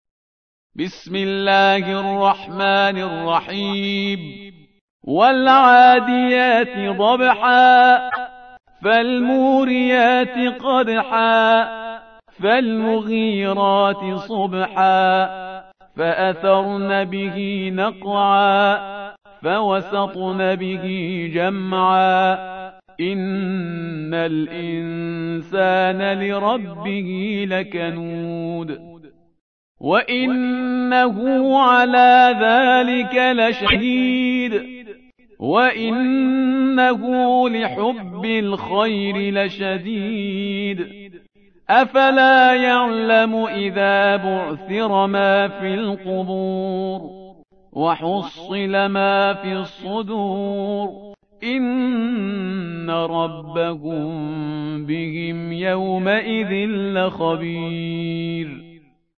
100. سورة العاديات / القارئ